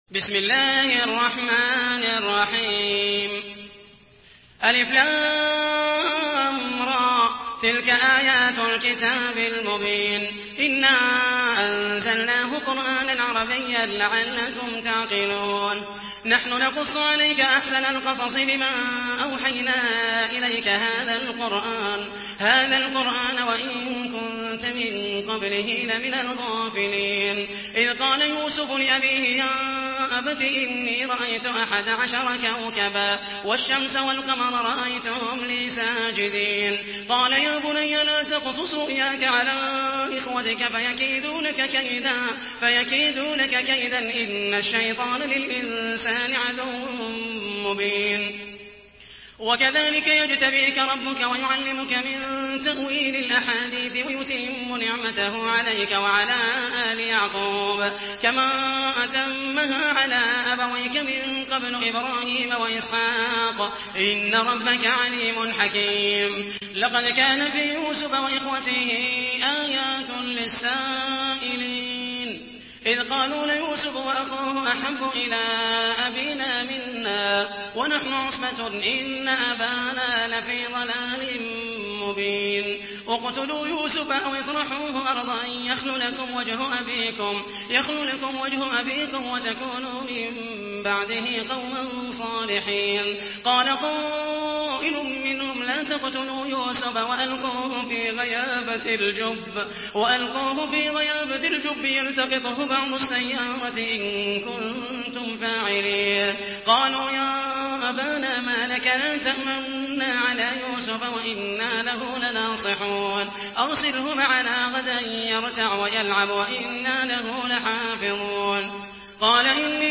Surah Yusuf MP3 by Muhammed al Mohaisany in Hafs An Asim narration.